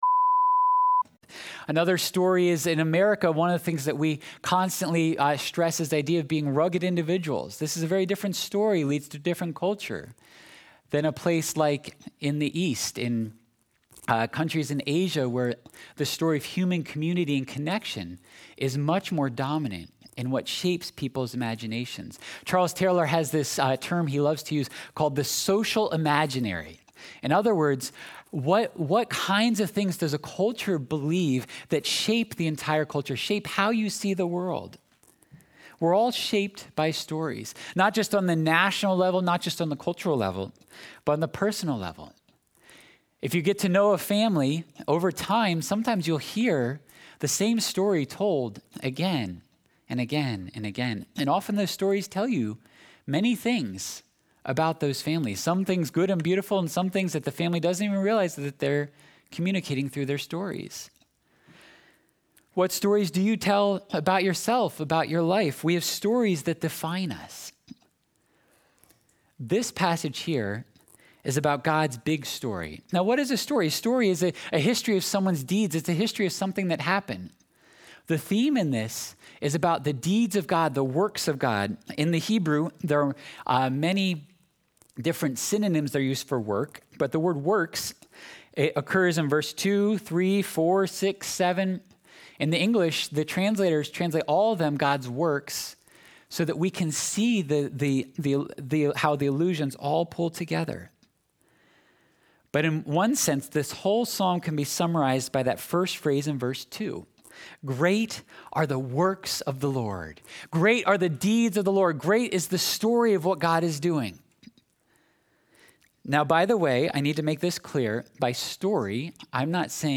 8.22.21-sermon-audio.mp3